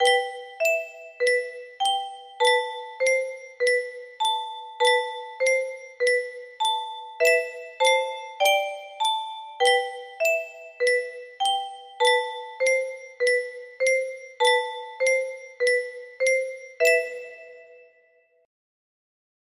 Track 1 � music box melody